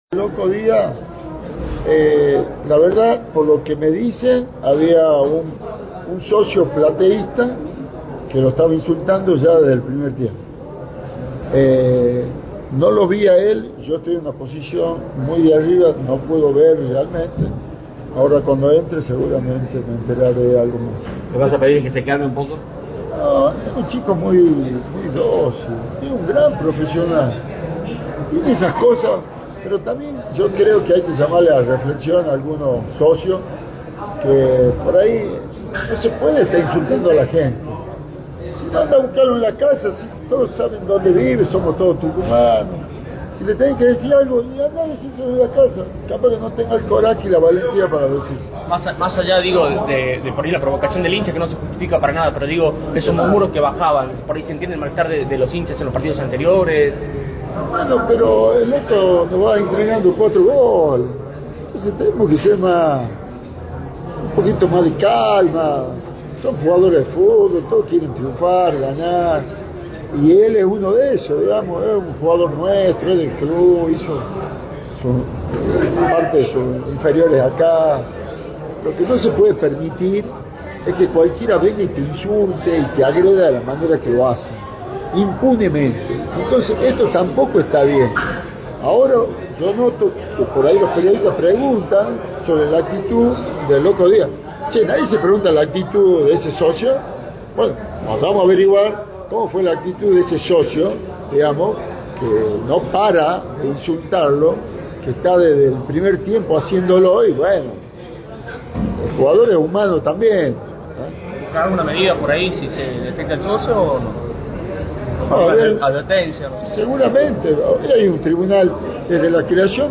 Contó que recibió versiones sobre insultos desde el primer tiempo y llamó a la reflexión de los socios. En tono firme, también dejó una frase que hizo ruido “Si no, andá a buscarlo a la casa. Si todos saben dónde vive, somos todos tucumanos. Si le tenés que decir algo, andá y decíselo en la casa. Capaz que no tengás el coraje y la valentía para decírselo”, al sugerir que los reclamos se hagan “cara a cara”.